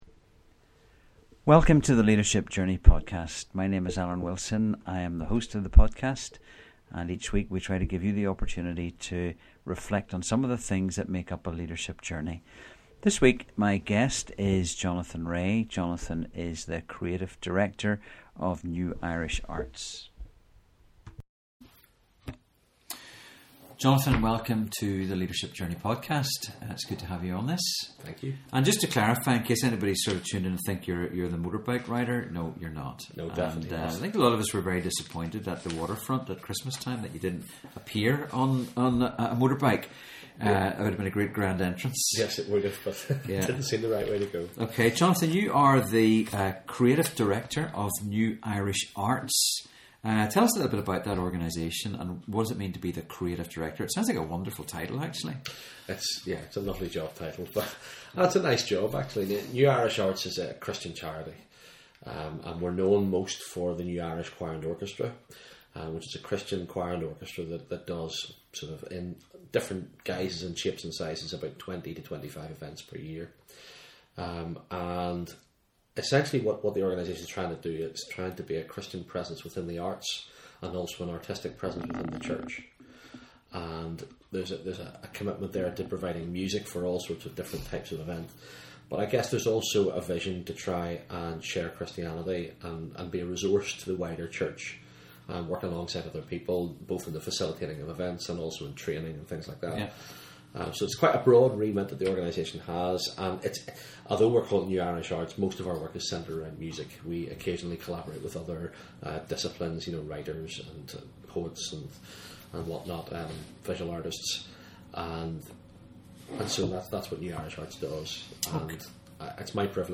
In this first part of the interview